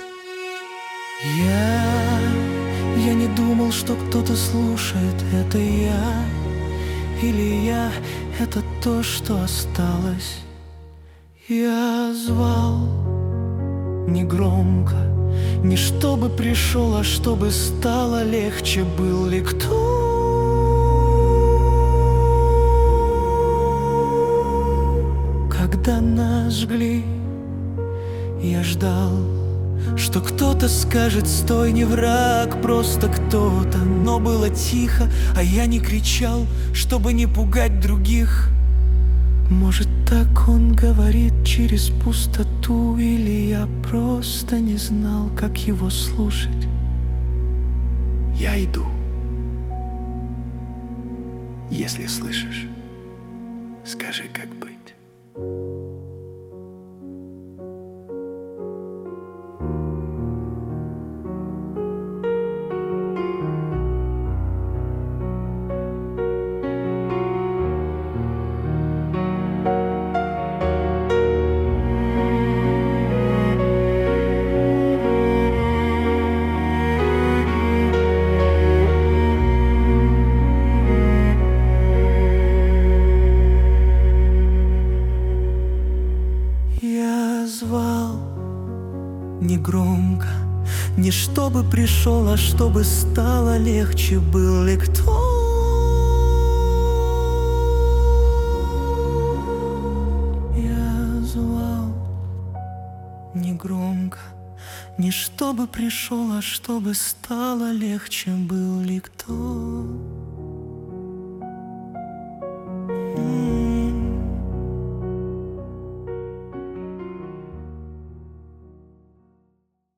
моно-исповедь подростка в шуме.